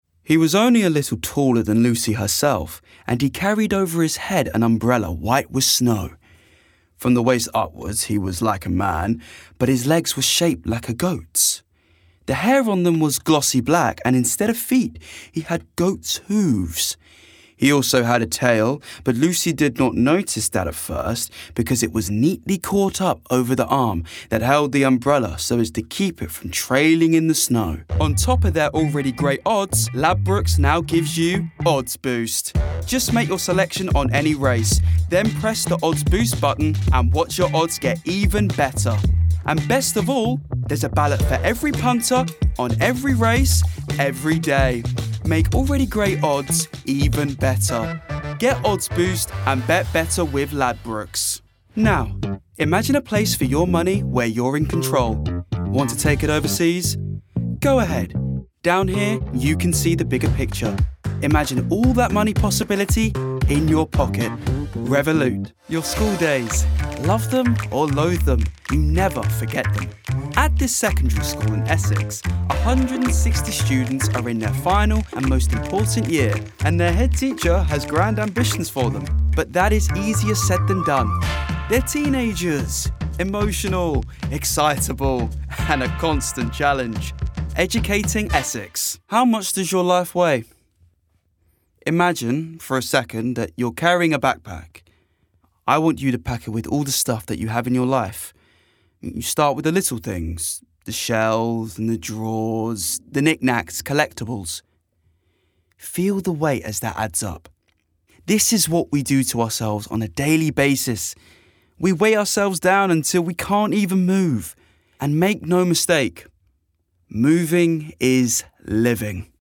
Essex
Voicereel:
BRITISH ISLES: Contemporary RP, Manchester, Northern-Irish
GLOBAL: Standard-American, American-Southern States
High Baritone